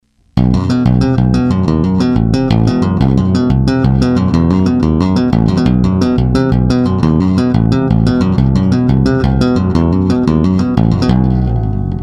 tapping :))
Tapping